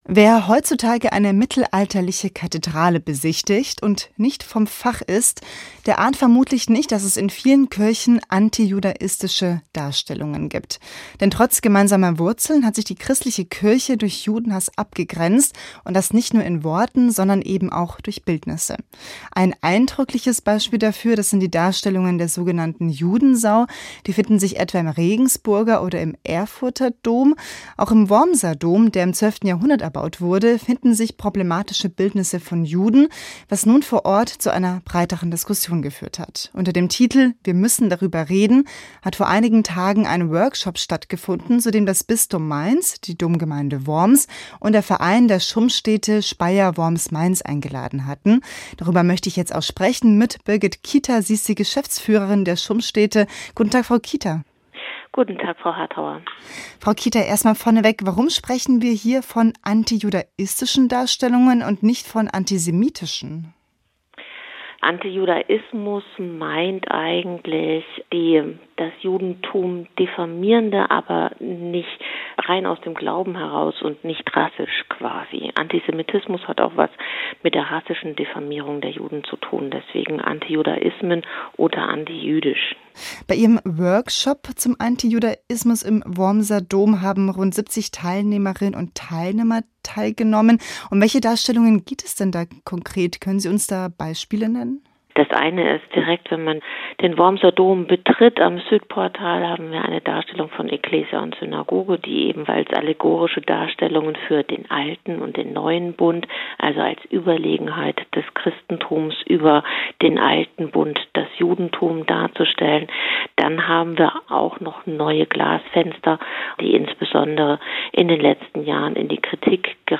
Diskussion über antijudaistische Motive im Wormser Dom